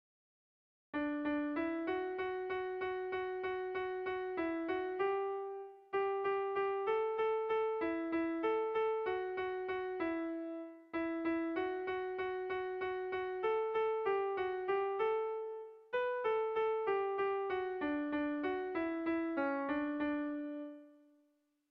Bertso melodies - View details   To know more about this section
Kontakizunezkoa
Zortziko txikia (hg) / Lau puntuko txikia (ip)
ABDE